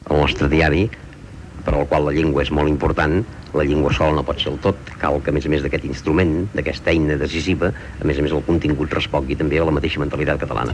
Informatiu
Extret de Crònica Sentimental de Ràdio Barcelona emesa el dia 5 de novembre de 1994.